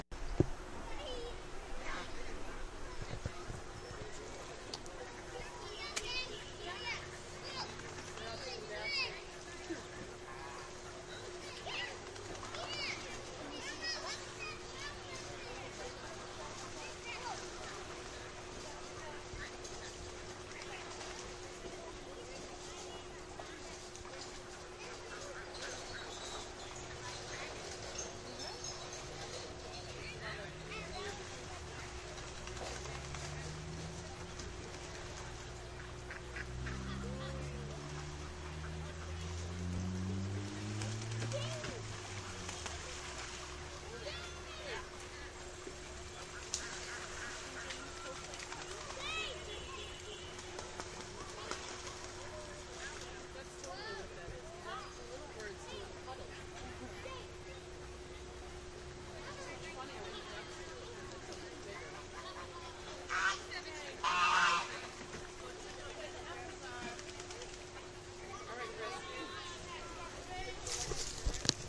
鸭子池塘氛围2
描述：记录了儿童在鸭池边喂鸭子的情景。 声音包括鸭子溅水、嘎嘎叫和拍打翅膀的声音，以及儿童和家庭的谈话声
Tag: 儿童 池塘 襟翼 嘎嘎 spash 翅膀